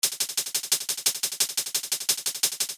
Get your hats sounding like a “ticka ticka” action sequence.
I’ll be using the default drum sounds from FL Studio, but this is transferable to any DAW.